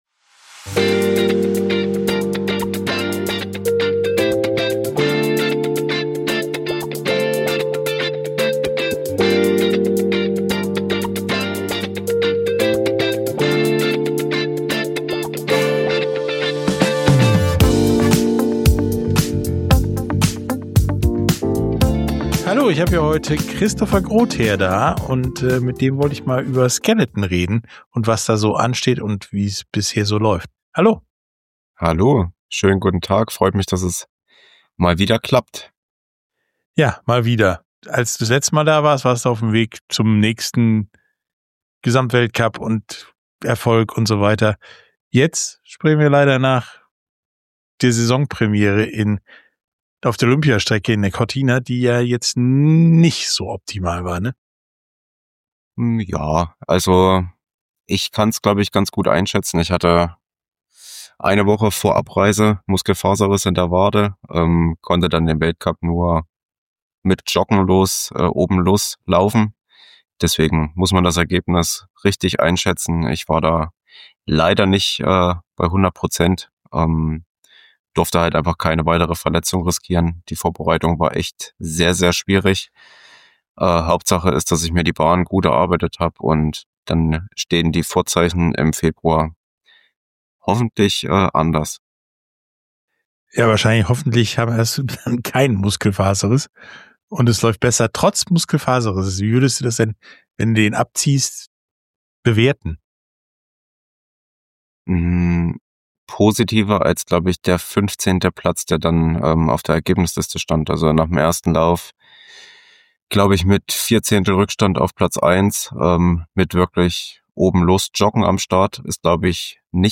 Sportstunde - Interview mit Christopher Grotheer, Skeleton Olympiasieger 2022 ~ Sportstunde - Interviews in voller Länge Podcast